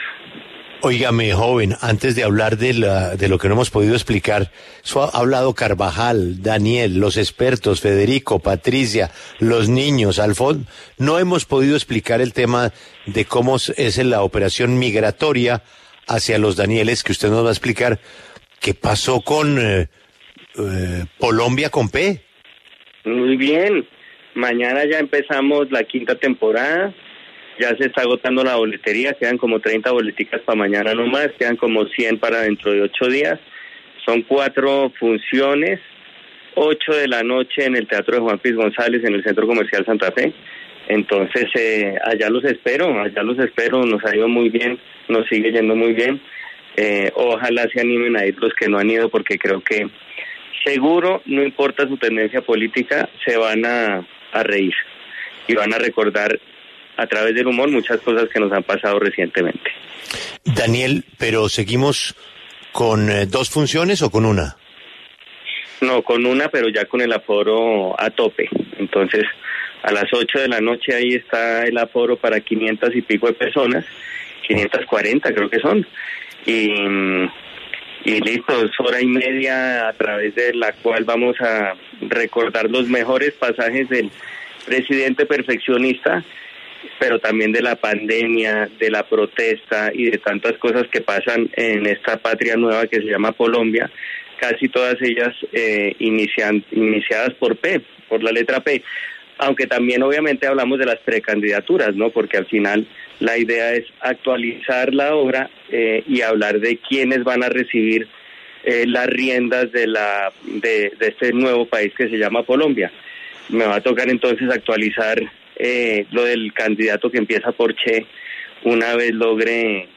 El periodista y humorista colombiano Daniel Samper Ospina le contó a Julio Sánchez Cristo en La W sobre la forma de cómo se podrá ver y leer la columna de Los Danieles en la nueva revista Cambio.